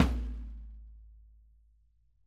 ropesnare-low-tsn-main-vl4-rr1.mp3